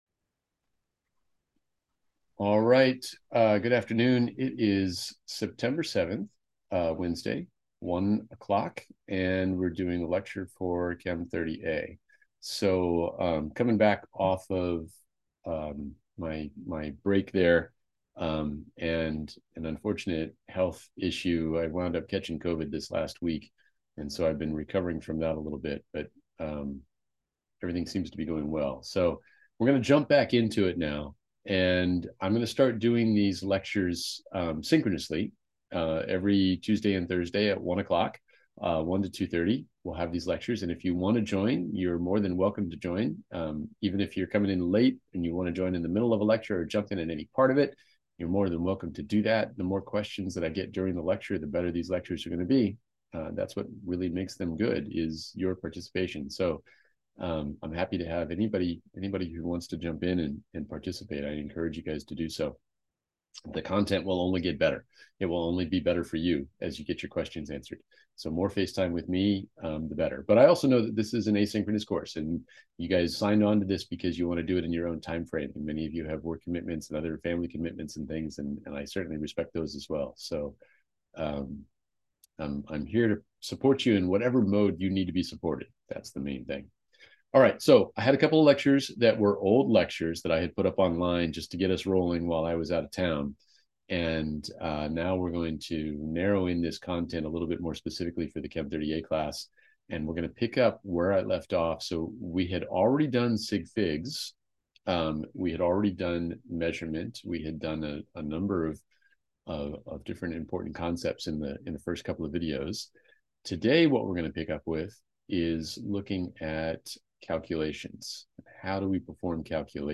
Chem 30A Zoom Lecture Recordings